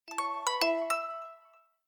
Звуки телефона Lenovo